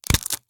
break_snare.ogg